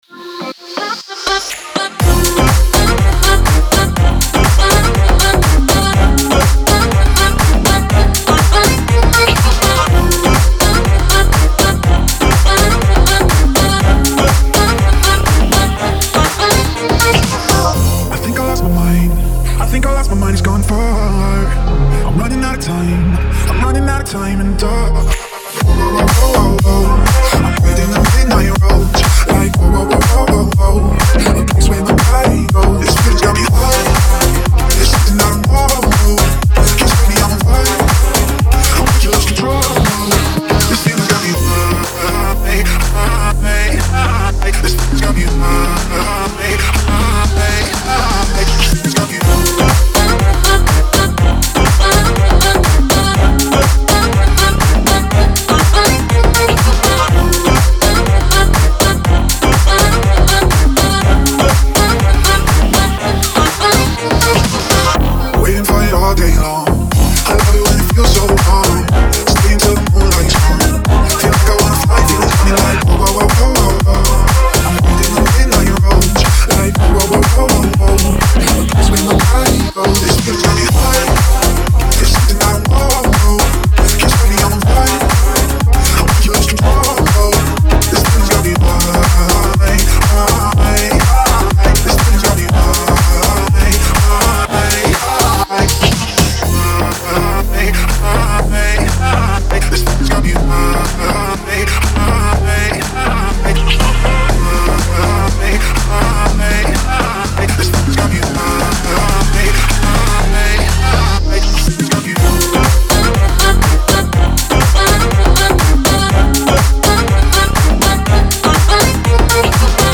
это зажигательный трек в жанре EDM